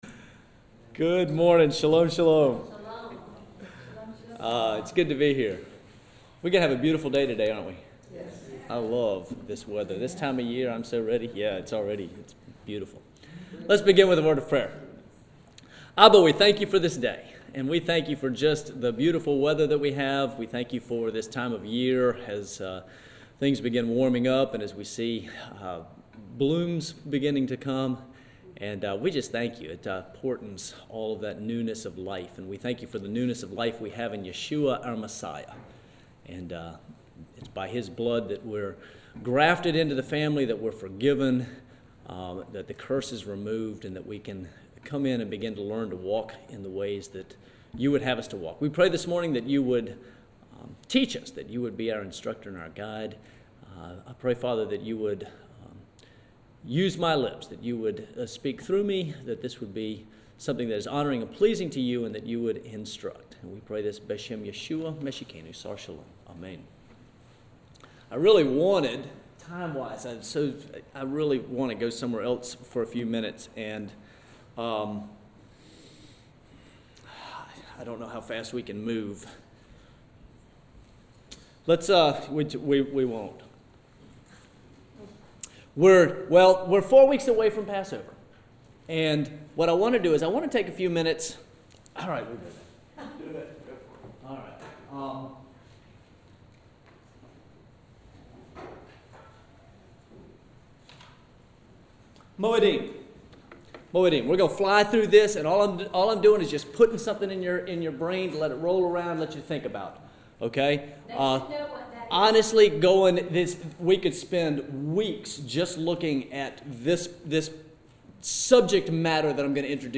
Sunday School, P.10 Moedim & Who is the Redeemer?